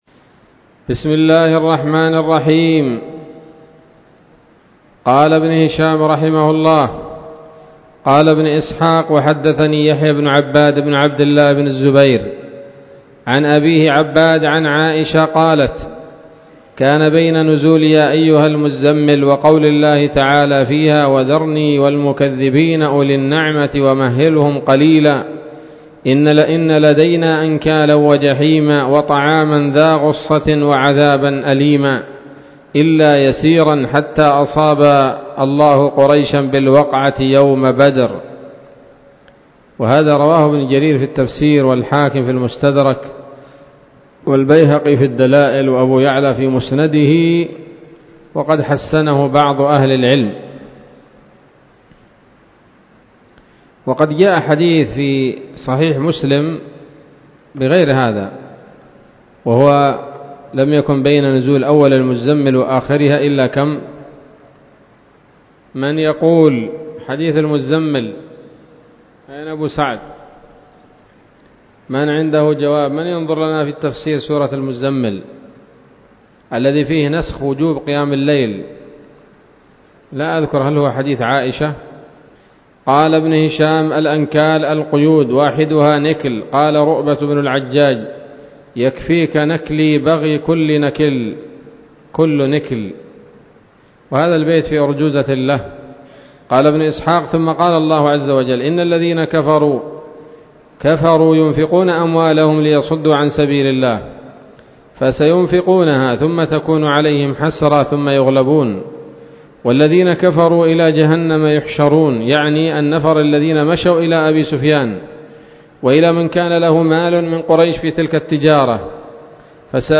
الدرس الحادي والثلاثون بعد المائة من التعليق على كتاب السيرة النبوية لابن هشام